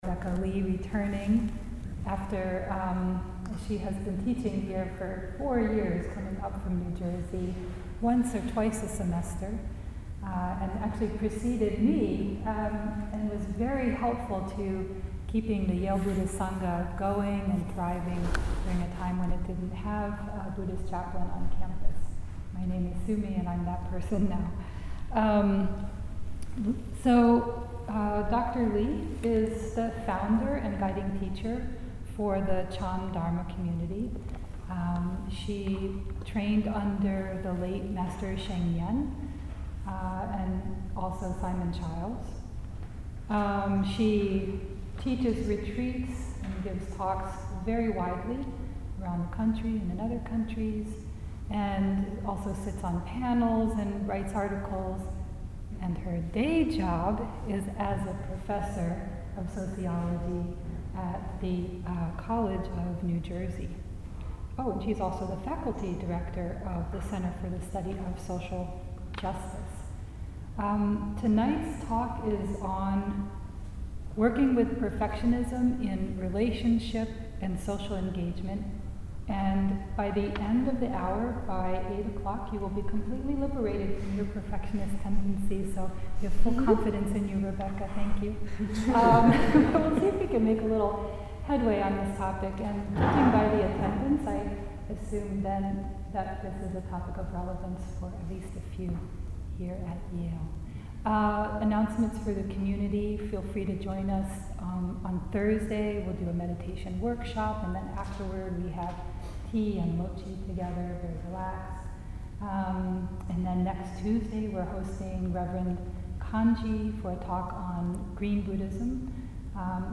This talk was given at the meditation workshop hosted by the Buddhist Sangha of Yale University on September 17, 2019. In this recording, the talk begins at the 17th minute.